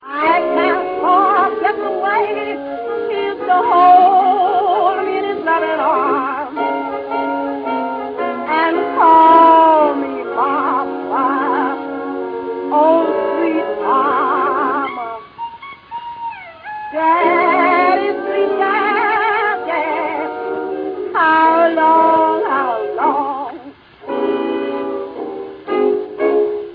вокал
фортепиано